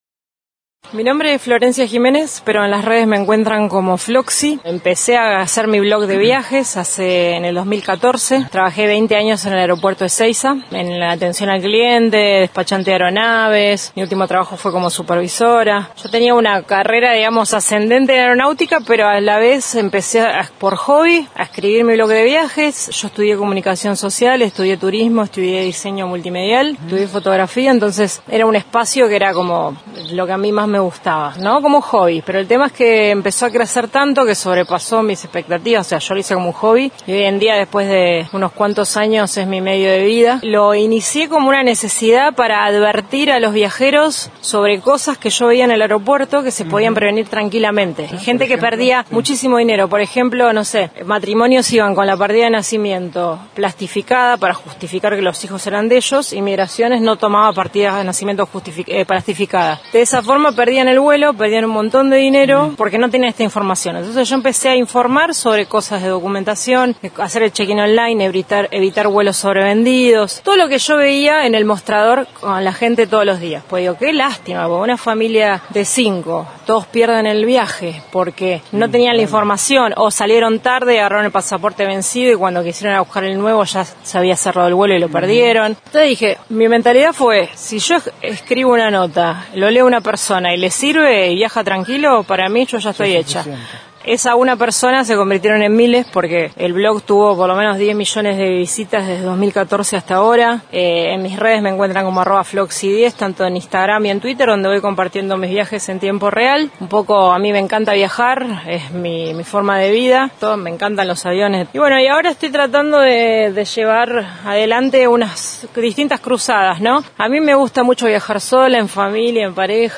En diálogo con Noticias de Esquel